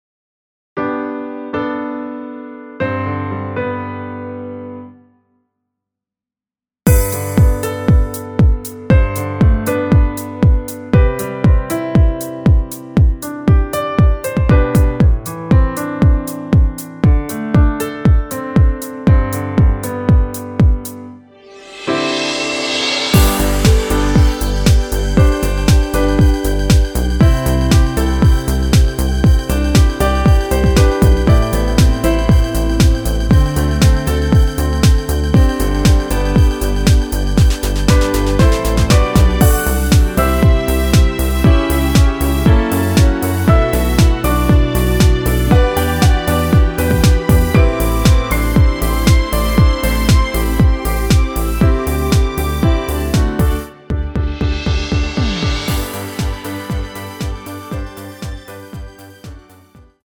전주가 없는 곡이라 2마디 전주 만들어 놓았습니다.(미리듣기 참조)
원키에서(+5)올린 MR 입니다
앞부분30초, 뒷부분30초씩 편집해서 올려 드리고 있습니다.